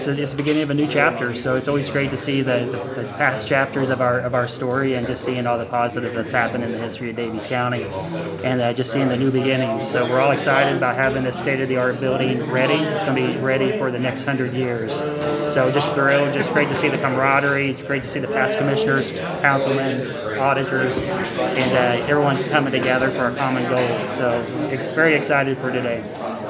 The Daviess County Board of Commissioners met in the Daviess County Courthouse on Tuesday for the final time.
President of the Board, Nathan Gabhart also shared his thoughts on the new government center…